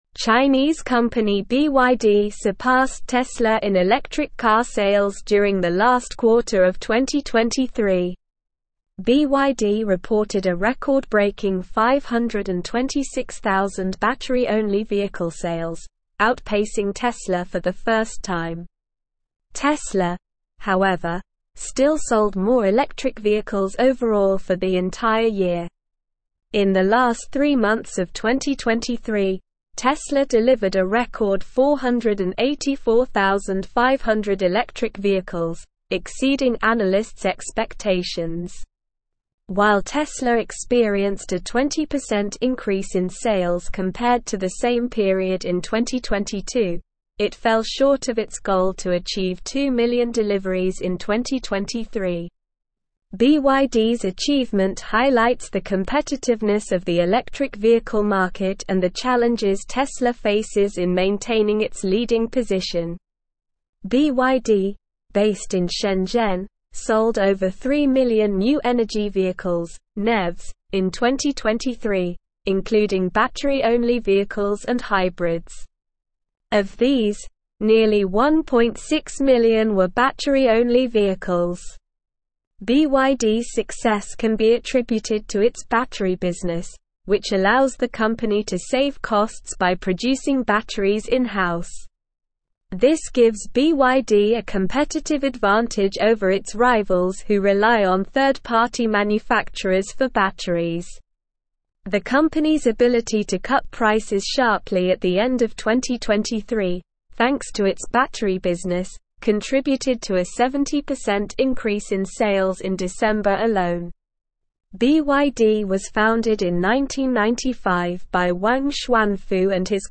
Slow
English-Newsroom-Advanced-SLOW-Reading-BYD-Overtakes-Tesla-in-Electric-Vehicle-Sales.mp3